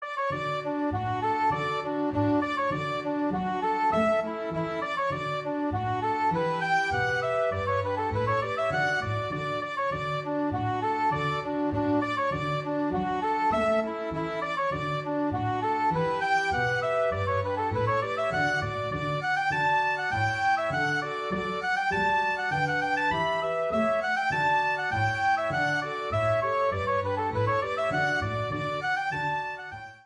a reel is a lively dance in 2/4 or 4/4.